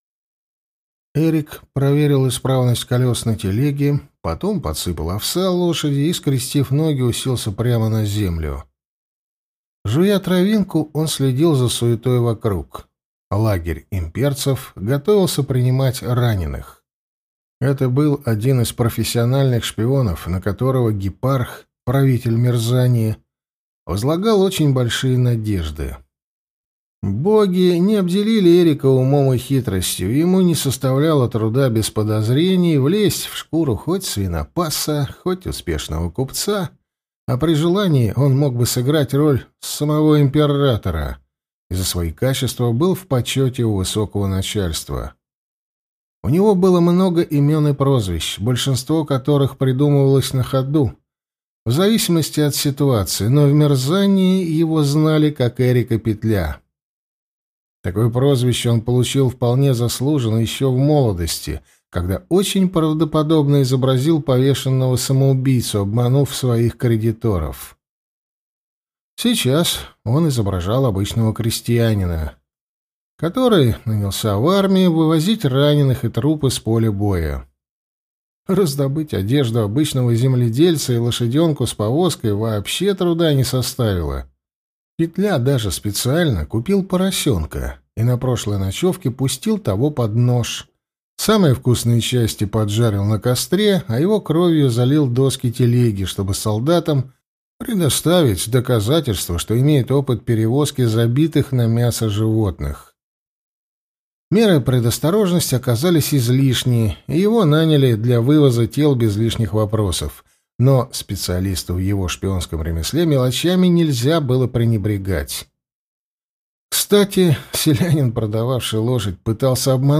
Аудиокнига Пешки богов-2. Наследница. Дар демона | Библиотека аудиокниг